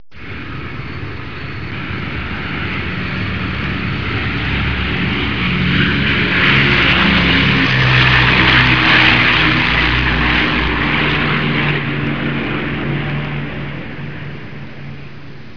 دانلود آهنگ طیاره 23 از افکت صوتی حمل و نقل
دانلود صدای طیاره 23 از ساعد نیوز با لینک مستقیم و کیفیت بالا
جلوه های صوتی